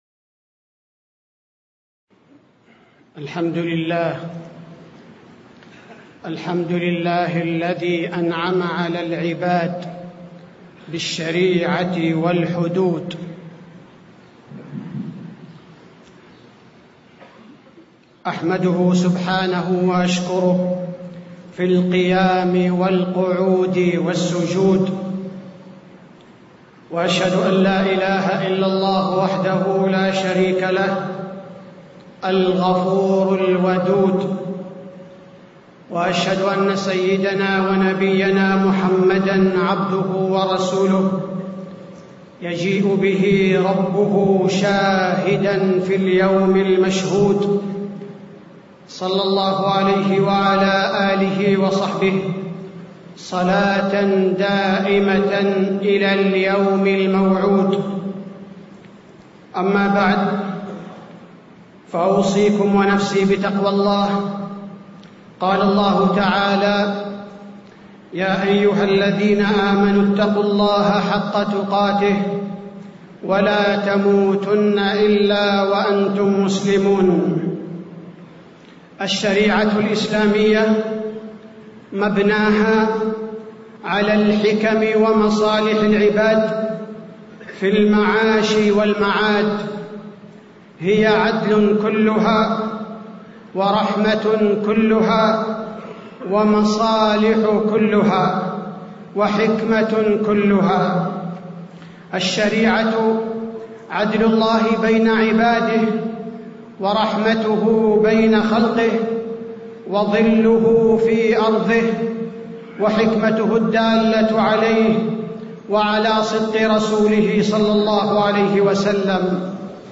تاريخ النشر ٢٨ ربيع الأول ١٤٣٧ هـ المكان: المسجد النبوي الشيخ: فضيلة الشيخ عبدالباري الثبيتي فضيلة الشيخ عبدالباري الثبيتي الحدود الشرعية أهميتها وحكمها